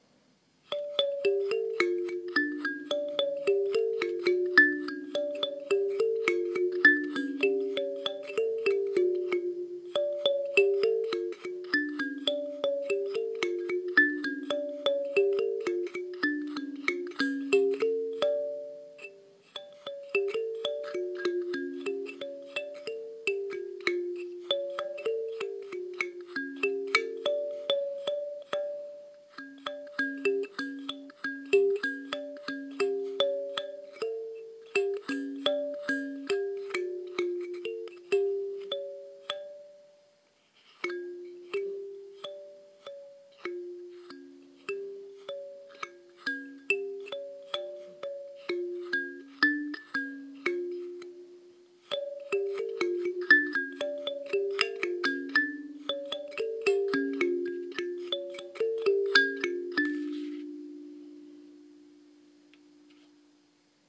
African thumb piano
73689-african-thumb-piano.mp3